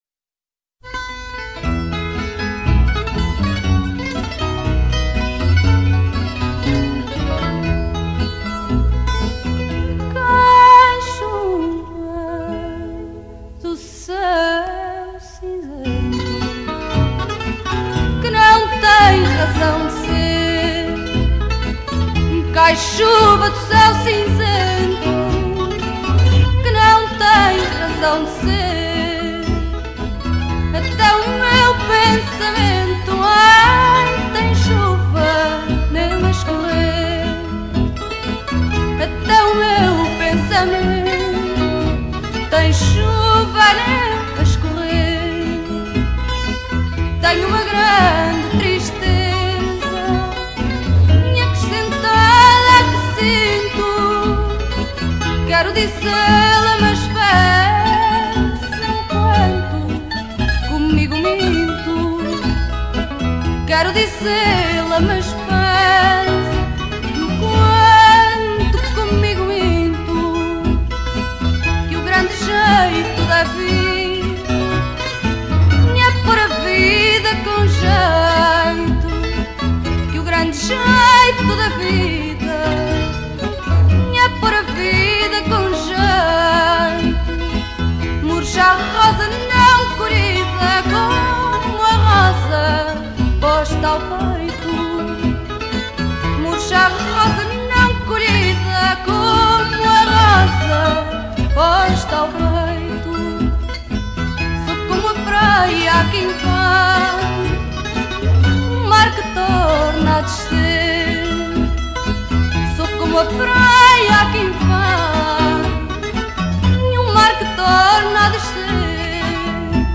Genre: Fado, Folk